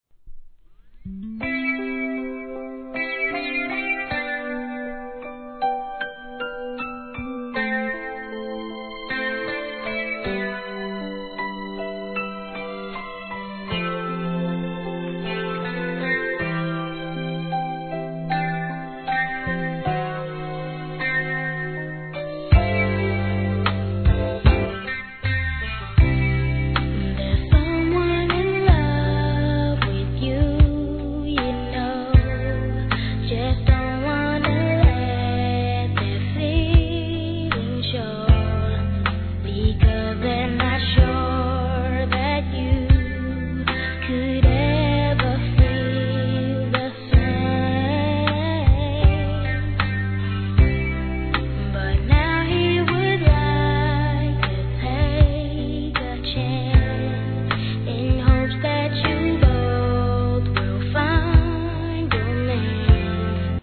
HIP HOP/R&B
まだ幼声ながら正統派ヴォーカリストの色がみえる4人組！